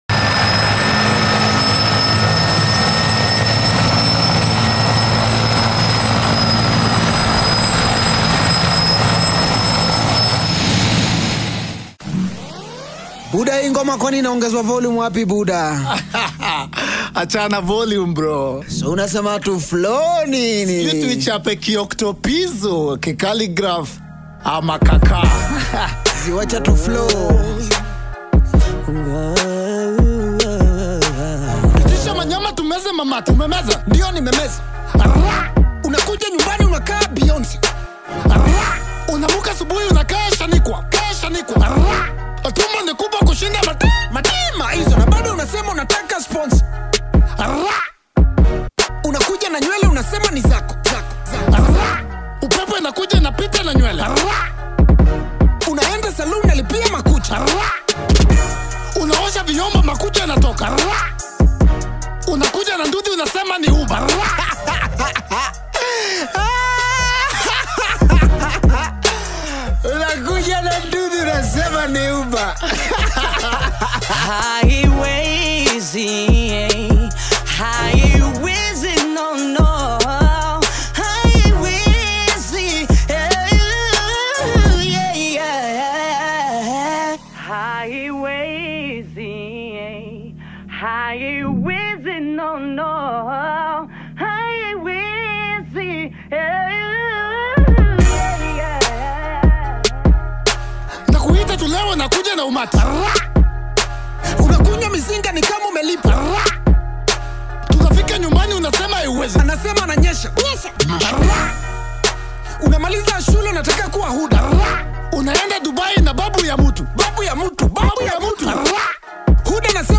Two Kenyan Comedians give their take on women and life.